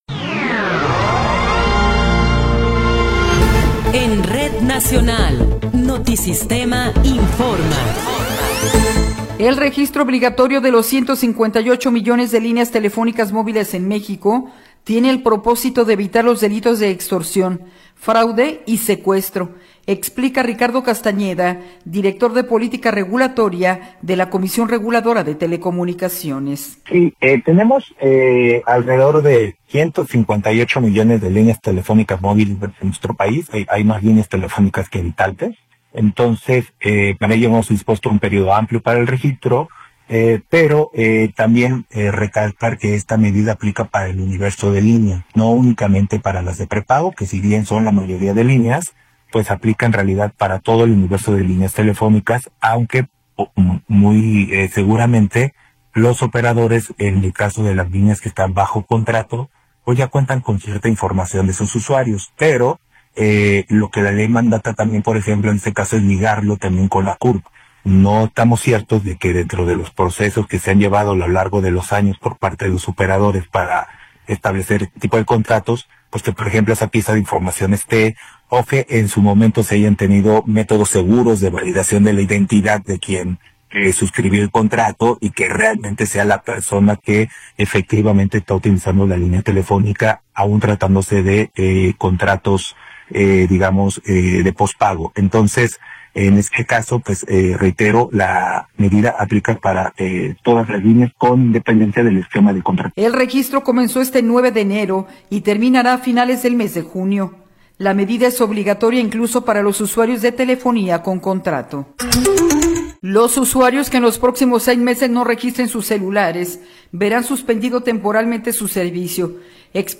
Noticiero 21 hrs. – 1 de Febrero de 2026